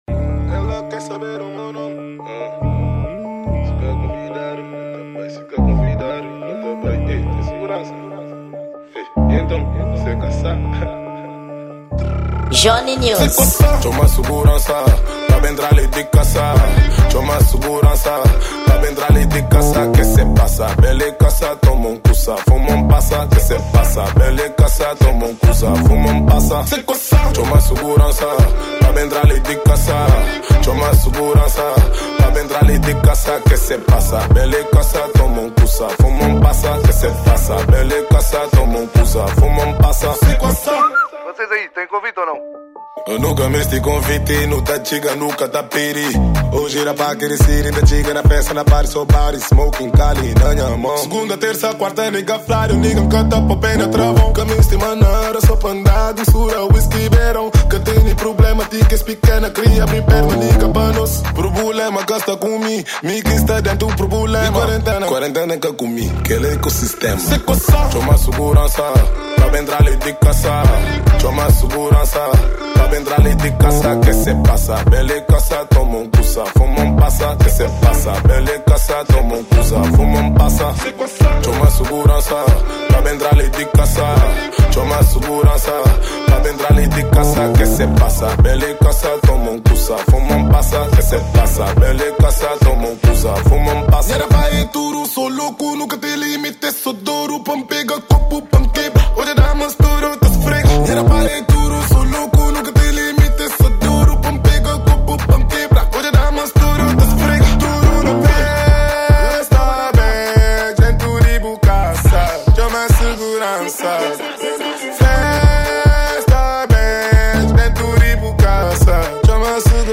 Gênero: Afro Pop